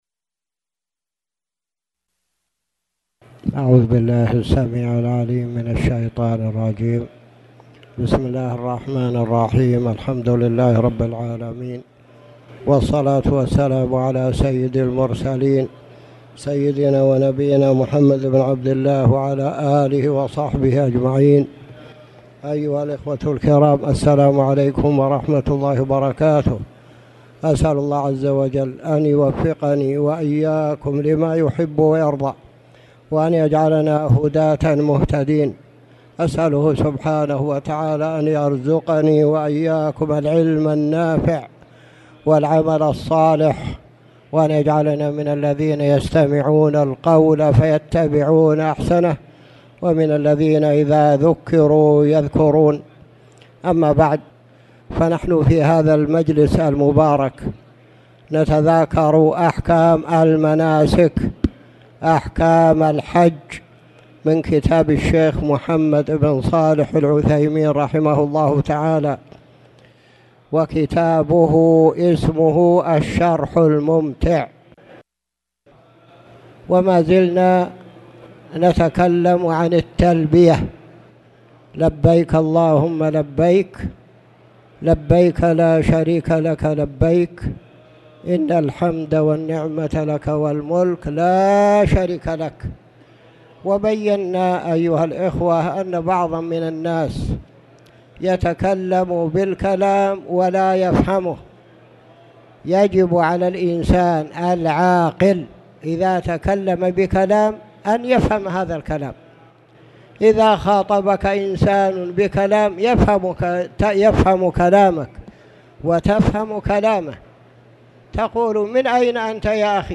تاريخ النشر ٢١ ذو القعدة ١٤٣٨ هـ المكان: المسجد الحرام الشيخ